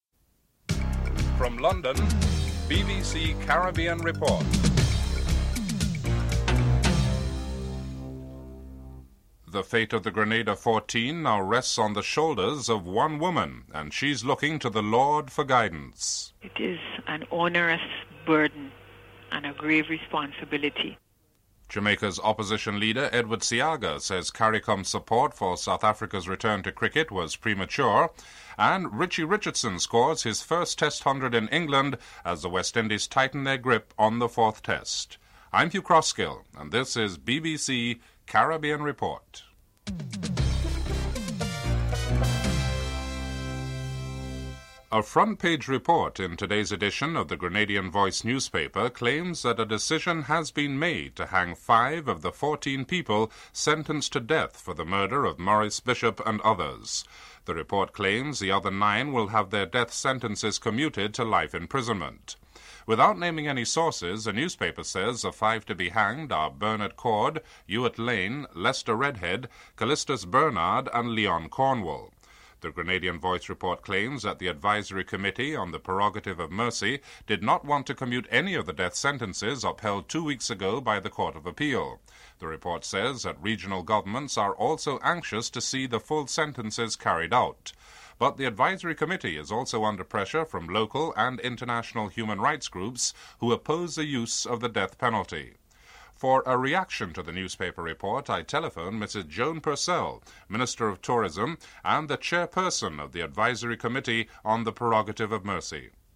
Headlines (00:00-00:44)
Interview with Jennifer Johnson, Sports and Youth Minister on the youth involvement in the coup and Barbados Prime Minister Erskine Sandiford comments on the security response by the region to the coup suggesting an expansion of the OECS based Regional Security System.